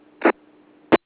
No beeps at all -
Top Left:  Hearing someone getting into Farnsworth, via Farnsworth.  Notice that there are NO beeps.
farn_farn_kerchunk_8k.wav